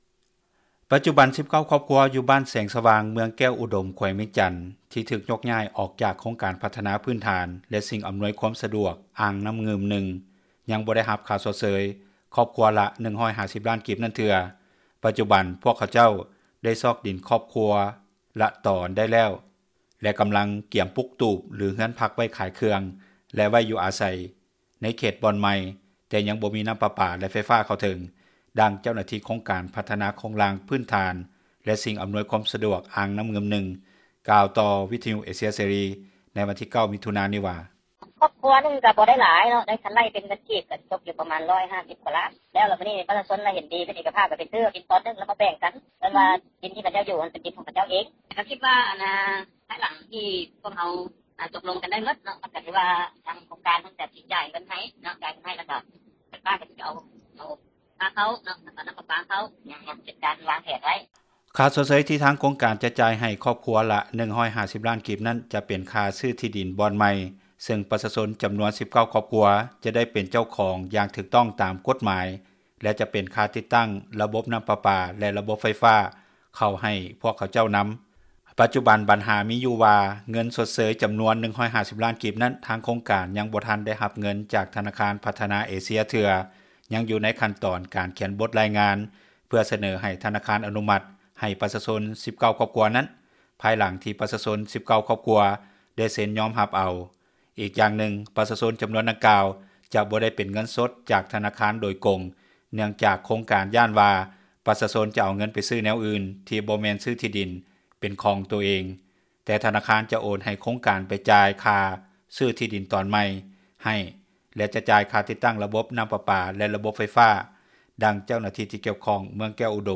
ດັ່ງເຈົ້າໜ້າທີ່ ທີ່ກ່ຽວຂ້ອງແຂວງວຽງຈັນ ກ່າວໃນມື້ດຽວກັນວ່າ: